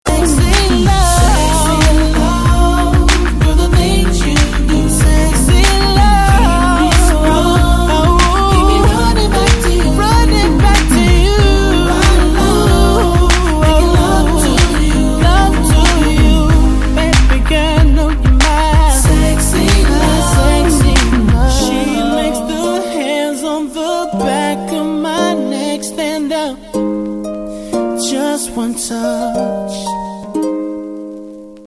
R'n'B